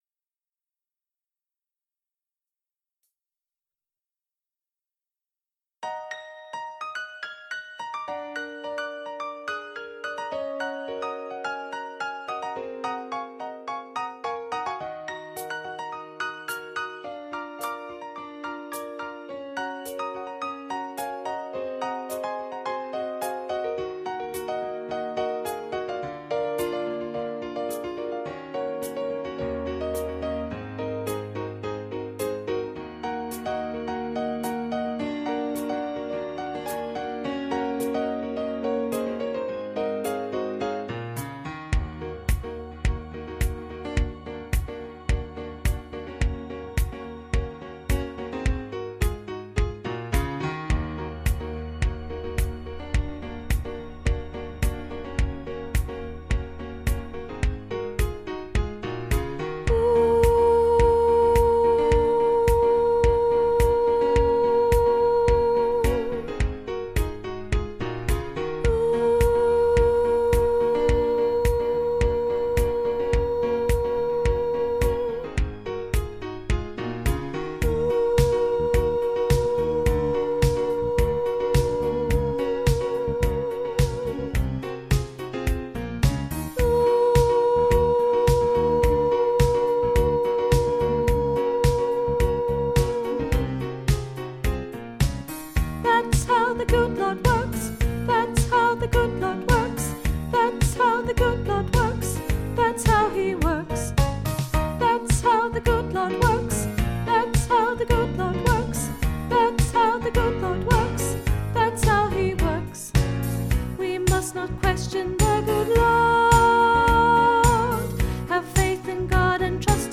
Stand on the Word Soprano & Bass - Three Valleys Gospel Choir